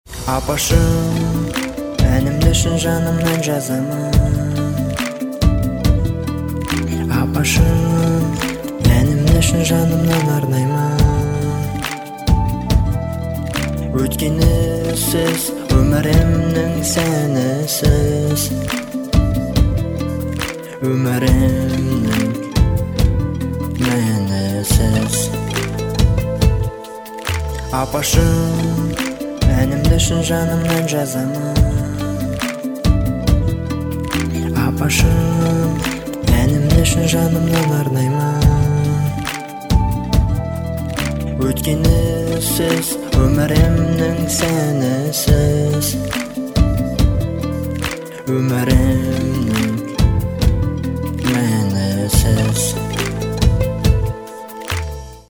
• Качество: 192, Stereo
душевные
спокойные
инструментальные
Народные
казахские